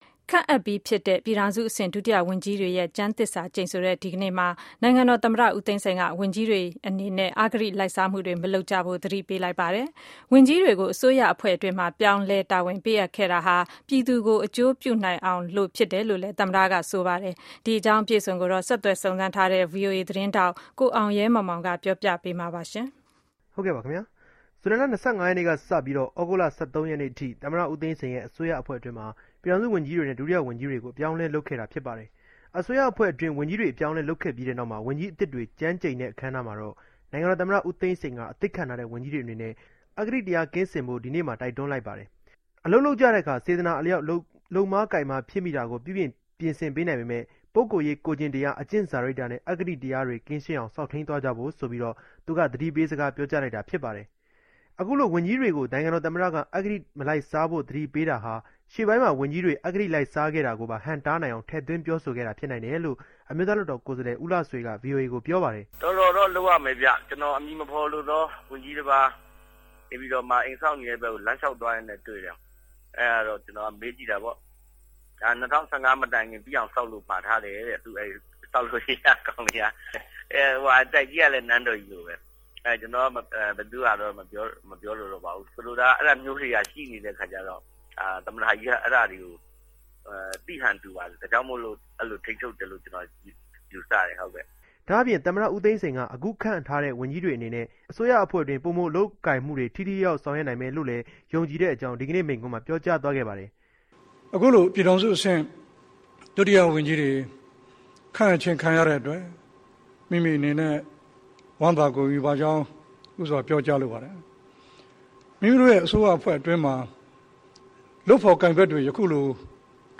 သမ္မတမိန့်ခွန်း